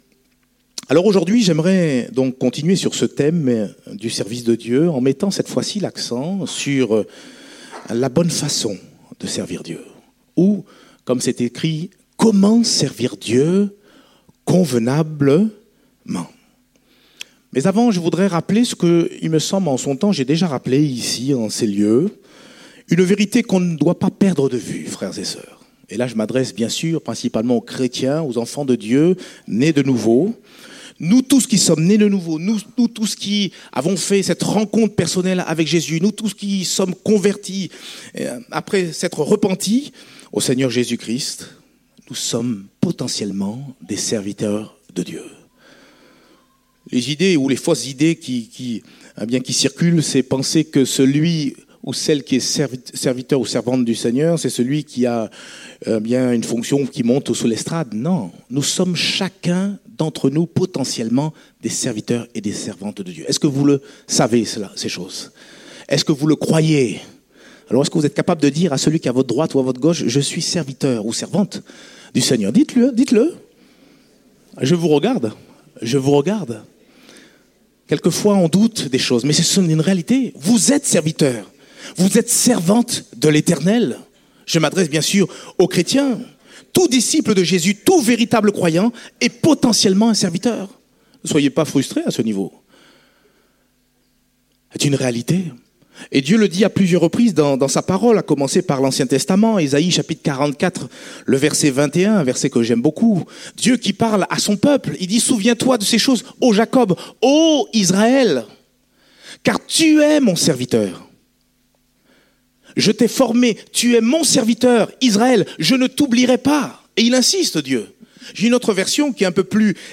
Date : 15 octobre 2017 (Culte Dominical)